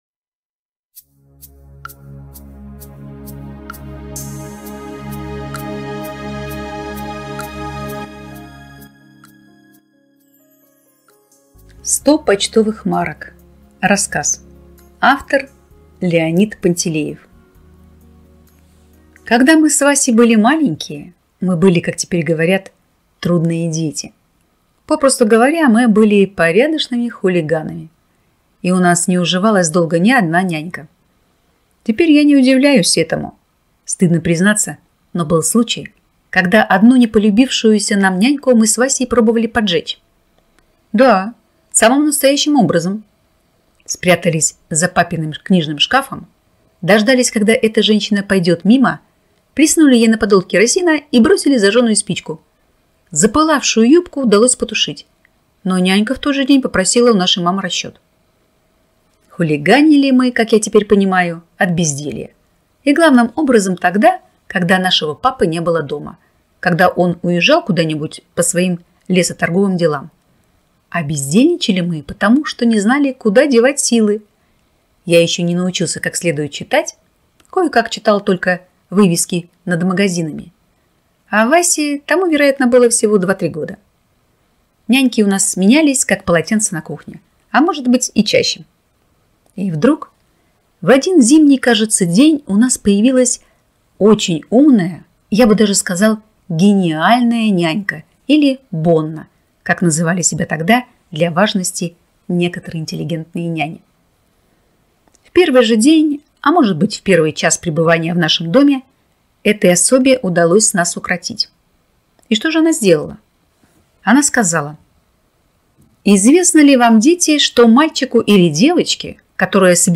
Сто почтовых марок - аудио рассказ Пантелеева - слушать